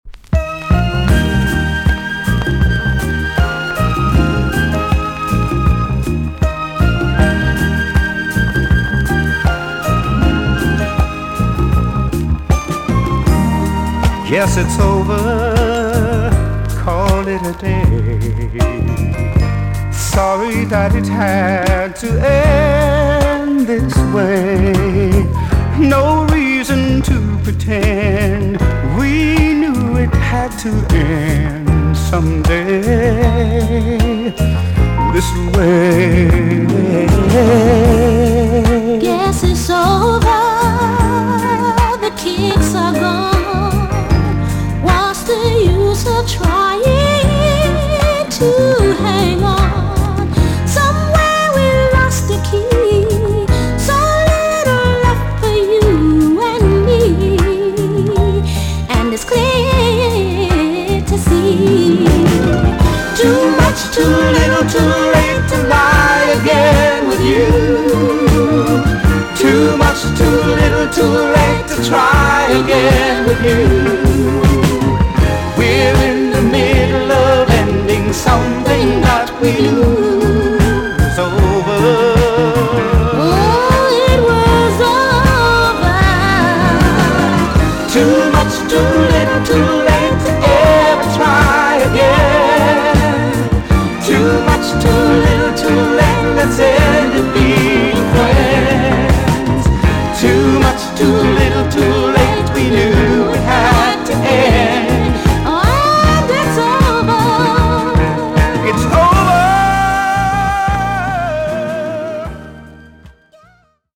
EX- 音はキレイです。
1978 , NICE JAMAICAN SOUL TUNE!!